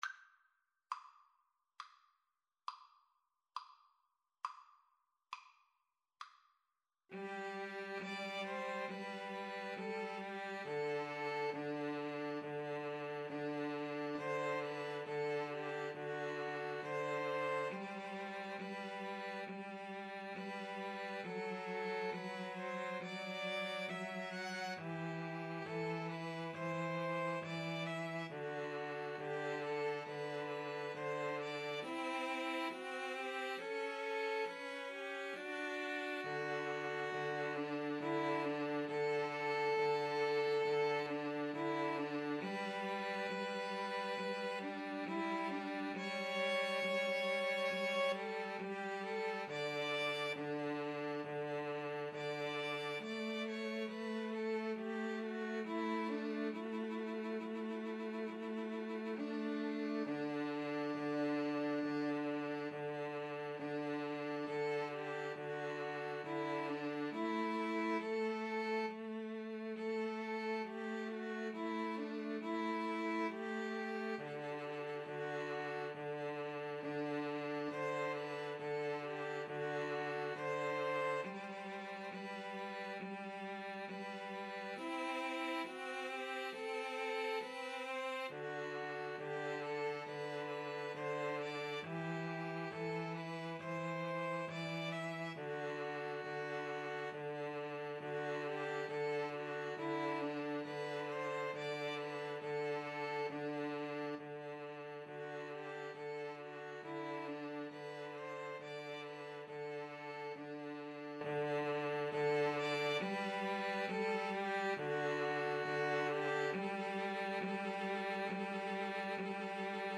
G major (Sounding Pitch) (View more G major Music for 2-Violins-Cello )
= 34 Grave
Classical (View more Classical 2-Violins-Cello Music)